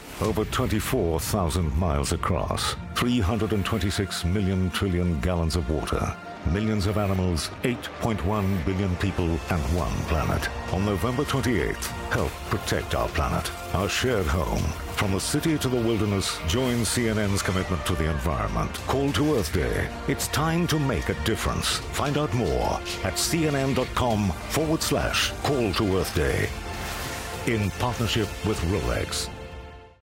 Male
Adult (30-50), Older Sound (50+)
Television Spots